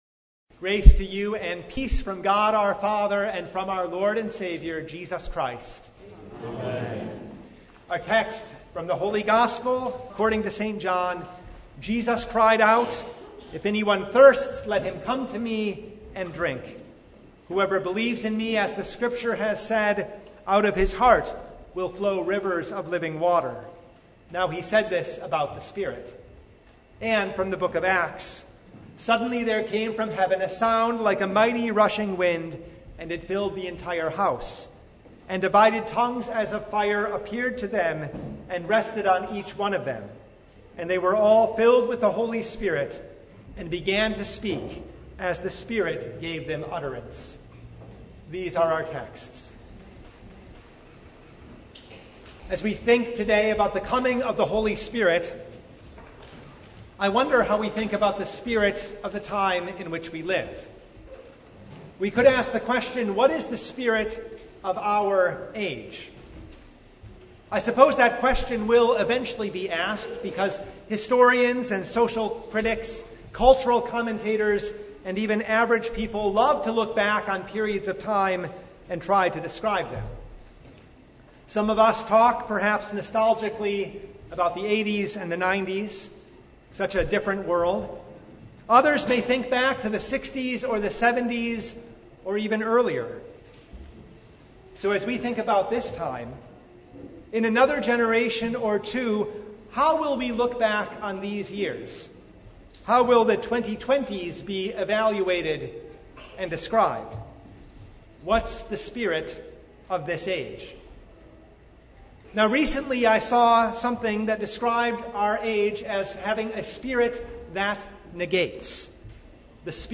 Service Type: The Feast of Pentecost